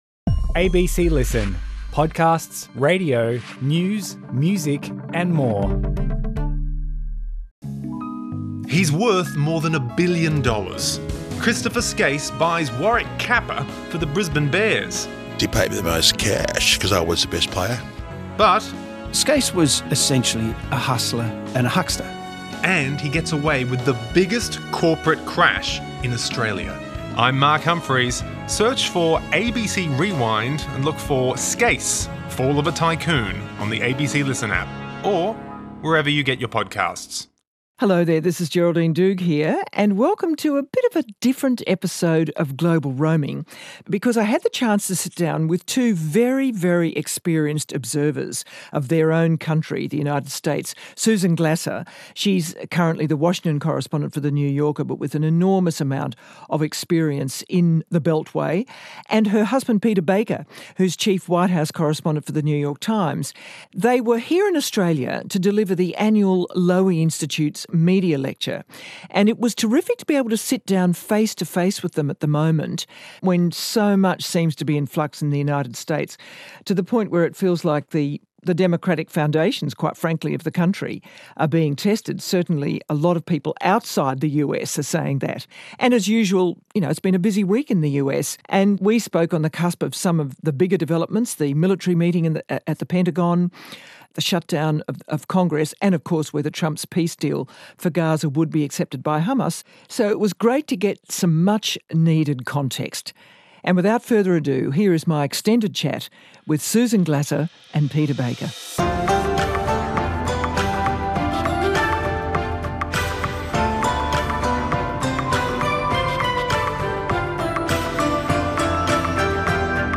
In a rare face-to-face conversation in Sydney, Geraldine Doogue sat down with veteran journalists Peter Baker (chief White House correspondent for The New York Times) and Susan Glasser (New Yorker correspondent and Lowy Institute board member) to discuss the psychology behind Donald Trump's war on 'the enemy within' and why a country like Australia should see this as a cautionary tale.